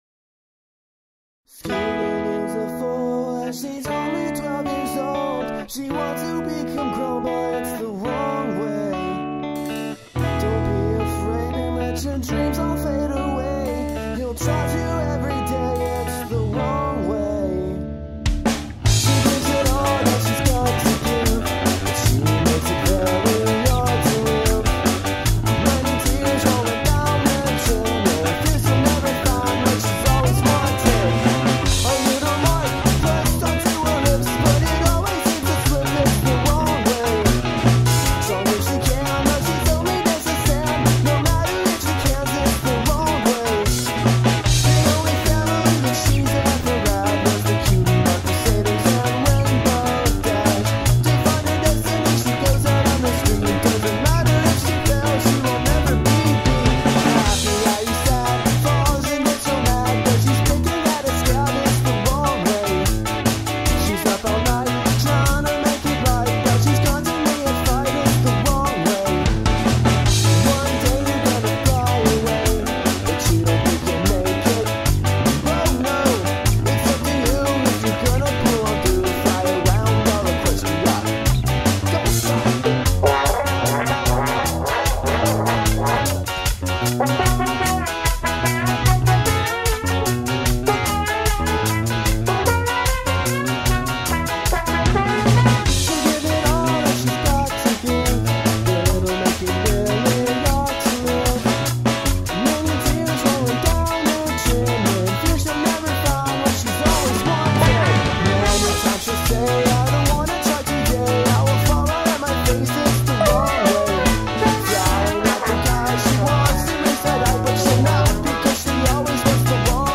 Trombone solo